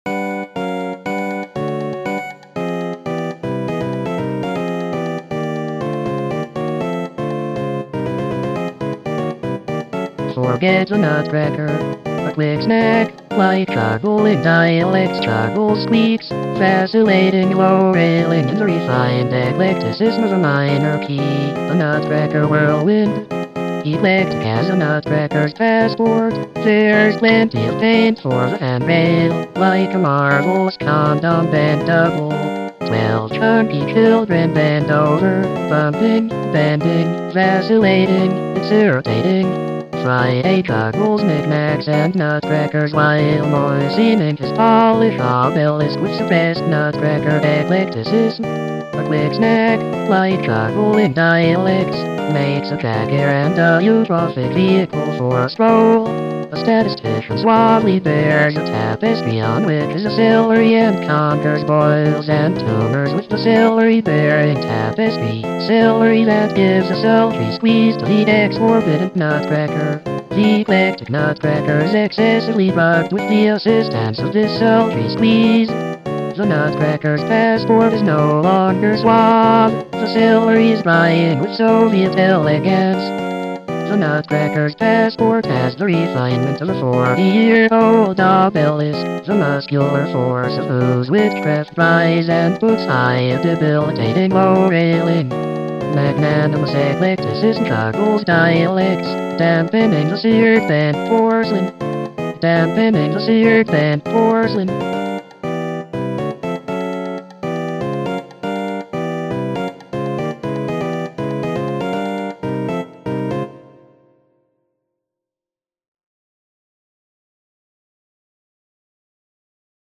Having done that, I decided that the text should be sung by a computer, and that it should be accompanied by de(con)structed Brazilian music. The voice synthesis was easily accomplished with the now defunct kids program Hollywood, and John Dunn's SoftStep was used to scramble the Ipaneman harmonies.